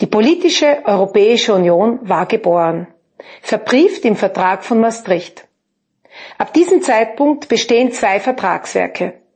austrian_accent.mp3